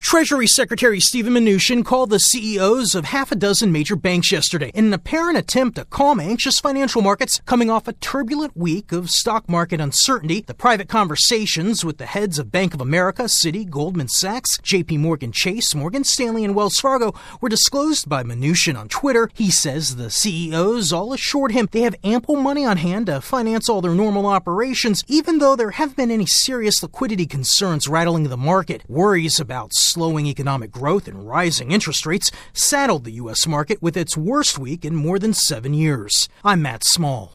The treasury secretary has sent an unusual tweet after a series of phone calls. AP correspondent